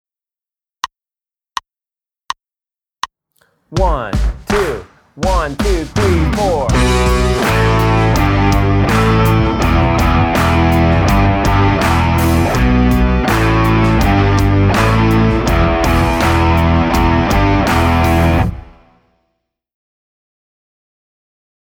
Voicing: Guitar